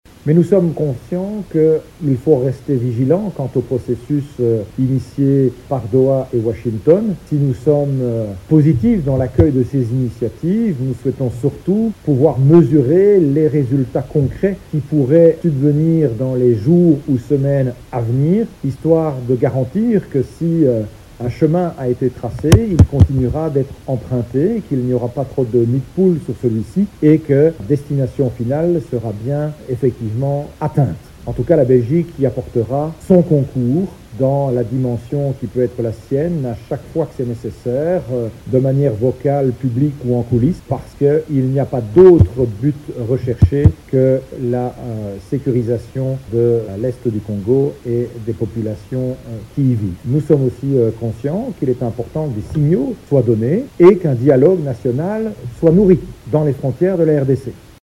Il l’a dit au cours d’une conférence de presse qu’il a animée lundi 28 avril, à Kinshasa.
Vous pouvez suivre les propos de Maxime Prévot: